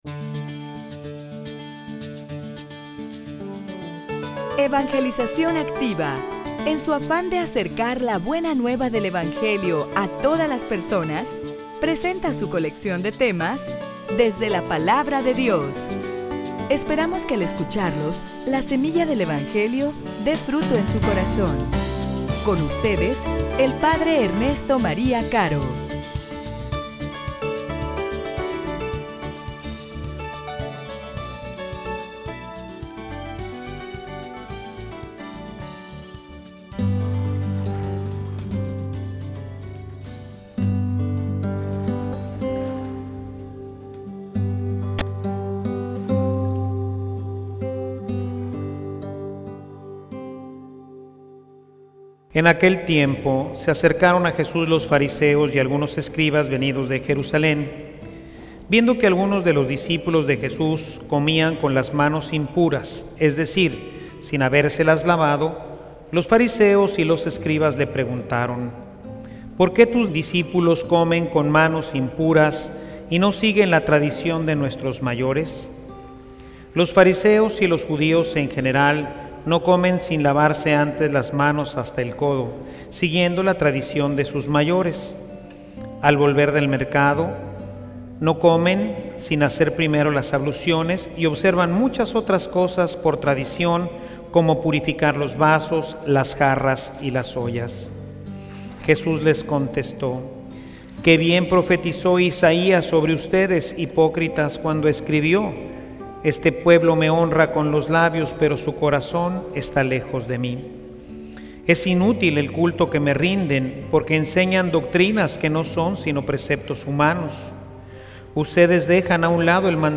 homilia_Construye_tu_vida_sobre_la_Roca.mp3